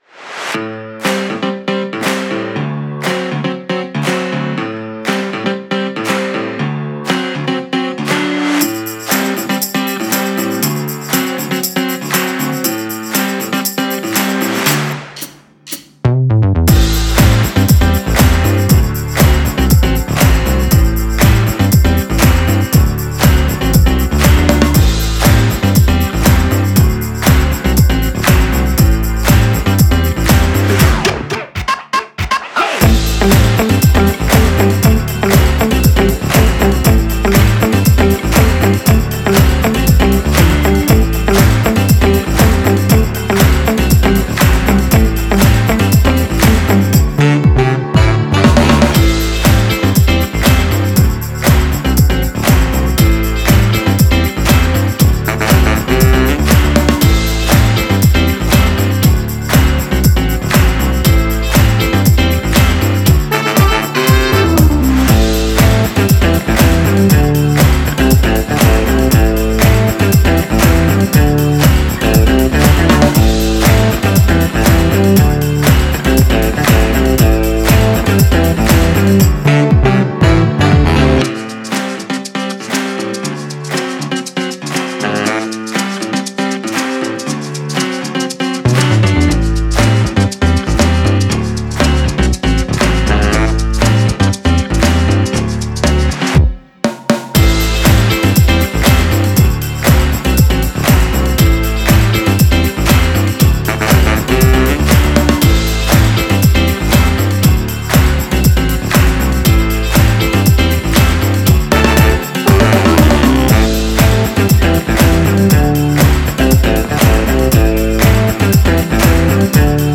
Genre: jazzfunk, funk.